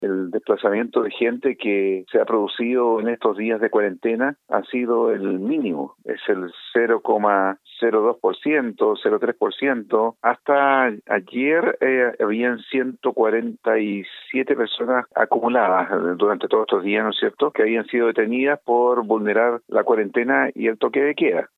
Así lo dijo el alcalde de la comuna, Omar Vera, quien agregó que el desplazamiento de las personas ha disminuido considerablemente.